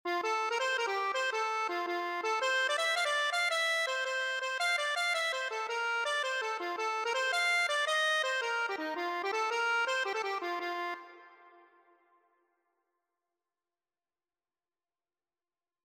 Accordion version
F major (Sounding Pitch) (View more F major Music for Accordion )
6/8 (View more 6/8 Music)
Accordion  (View more Easy Accordion Music)
Traditional (View more Traditional Accordion Music)